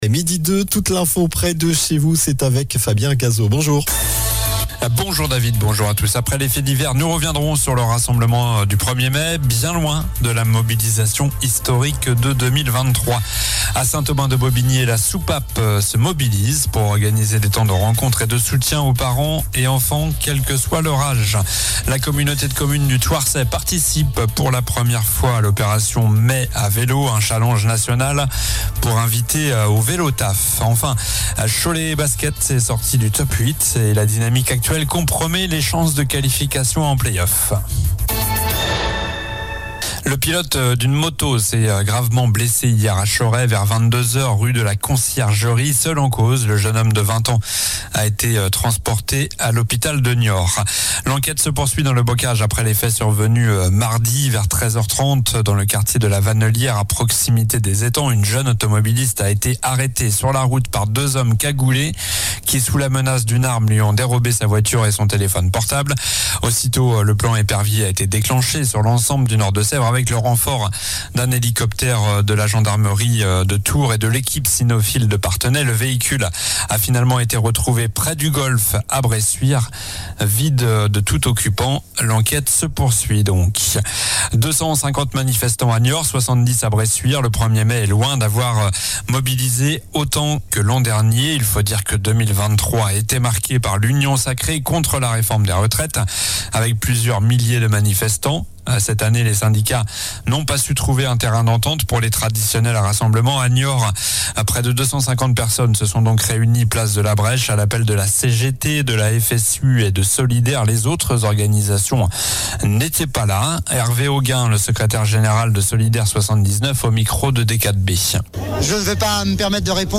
Journal du jeudi 02 mai (midi)